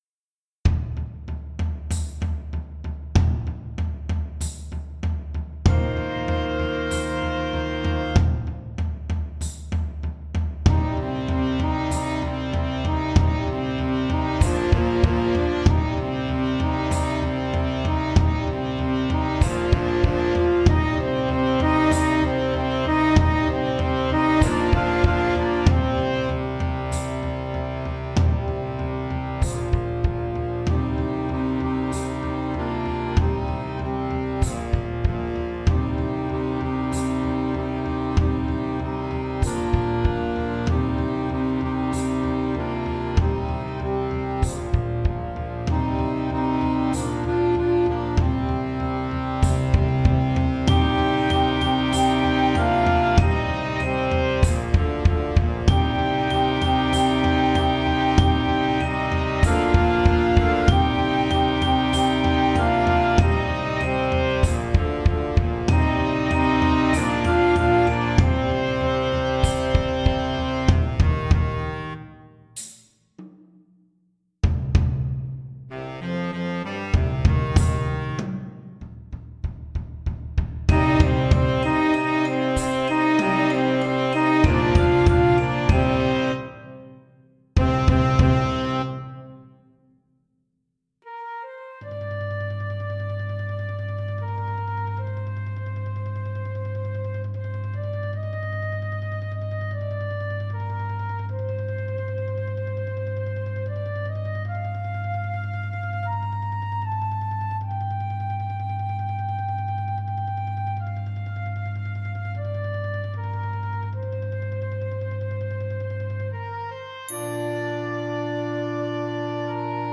Catégorie Harmonie/Fanfare/Brass-band
Sous-catégorie Musique de concert
Instrumentation Ha (orchestre d'harmonie)
Danses et chants